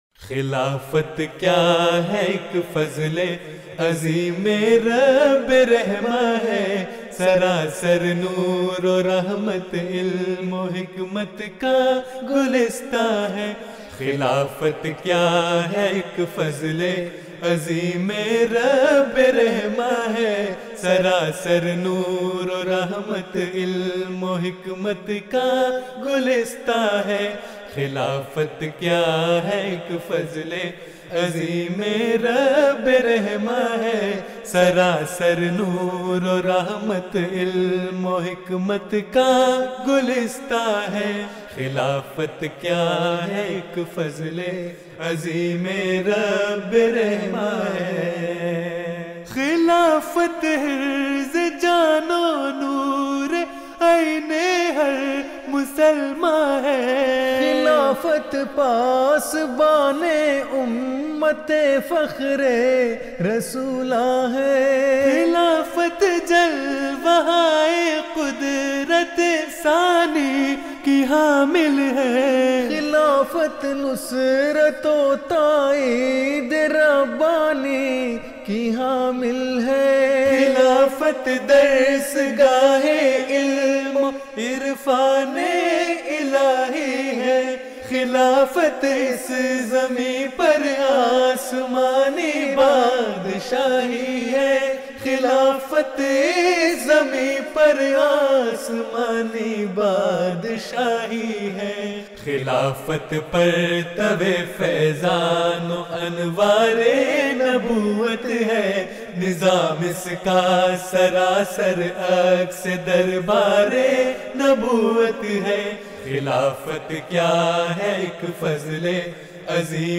نظمیں (Urdu Poems)
آواز: خدام گروپ Voice: Group Khuddam جلسہ سالانہ یوکے ۲۰۱۲ء Jalsa Salana UK 2012